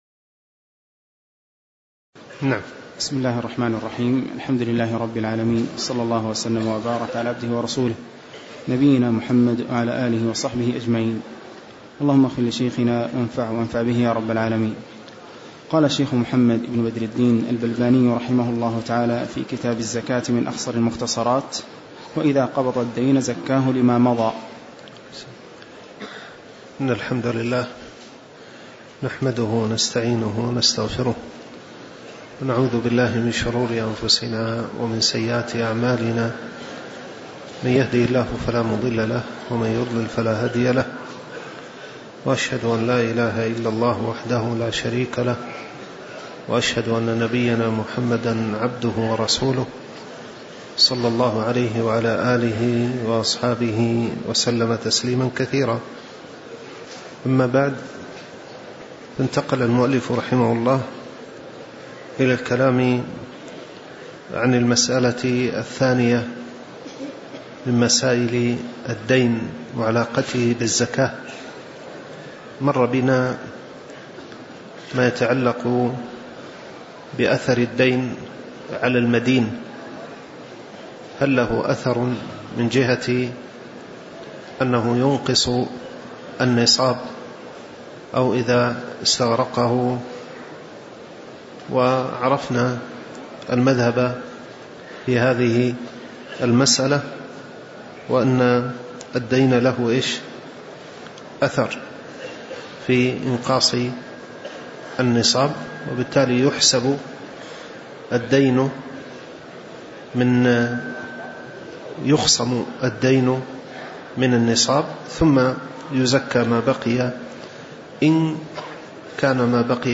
تاريخ النشر ٢ محرم ١٤٤٠ هـ المكان: المسجد النبوي الشيخ